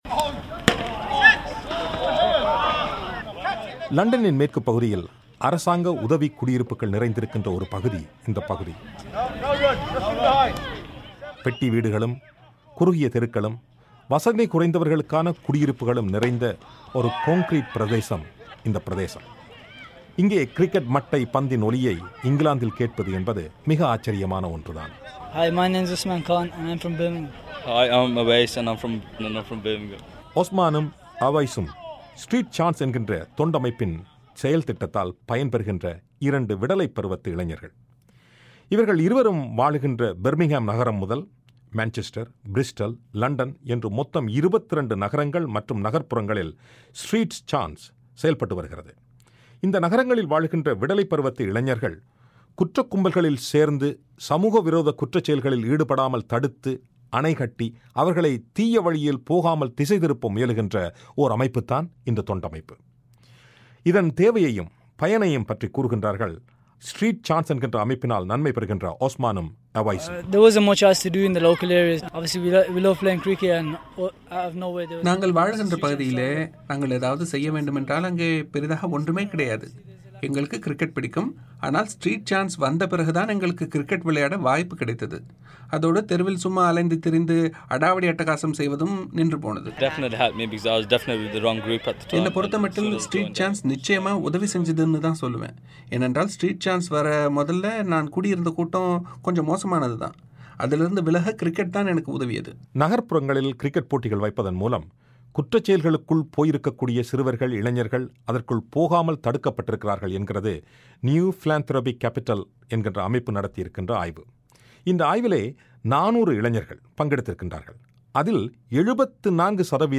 இதன் மூலம் போதை மருந்து, குற்றச்செயல்கள் போன்ற தவறான காரியங்களில் இளைஞர்கள் ஈடுபடுவது தடுக்கப்படுவதாக கூறப்படுவது பற்றிய ஓர் ஒலிப் பெட்டகம்.